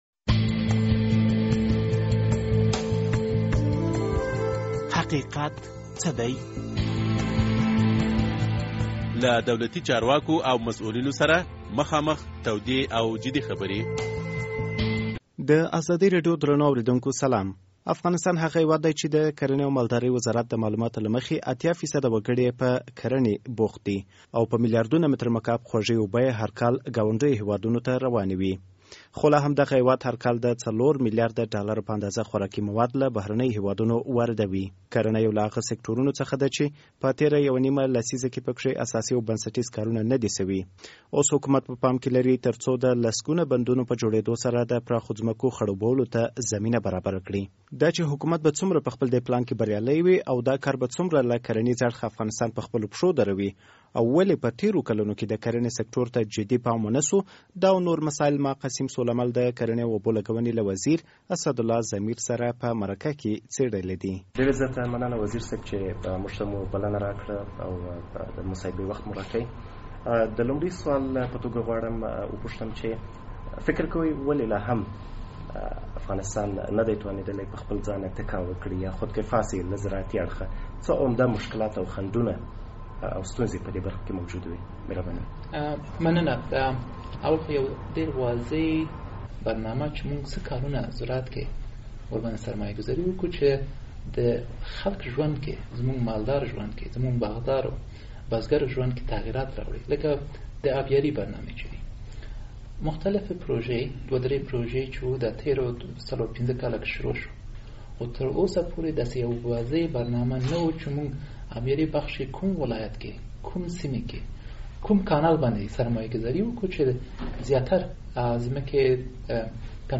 اسد الله ضمیر له ازادي راډيو سره مرکه کې وویل، اړتیا ده چې د کرنې په سکټور کې پراخه پانګونه وشي تر څو داخلي اړتیاوې له کورنیو تولیداتو پوره شي.